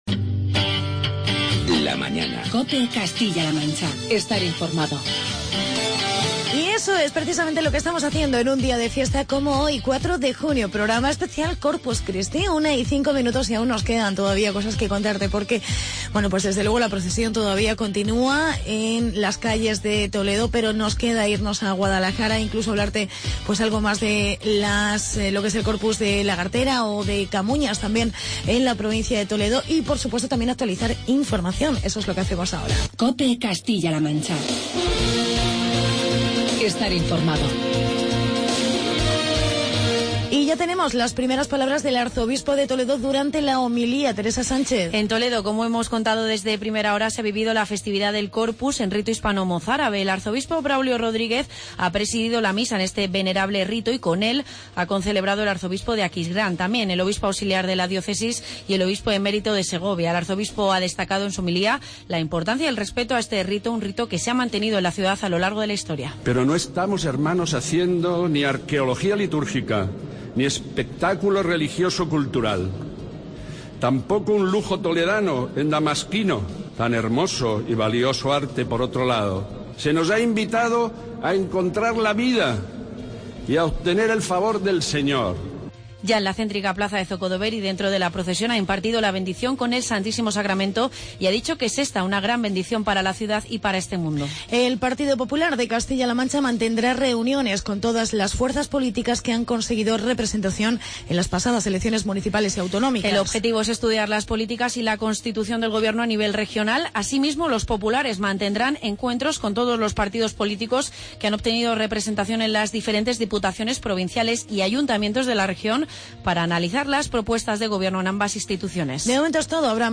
Reportaje del Corpus en Guadalajara y entrevista con la Asoc. de Floristas de Toledo.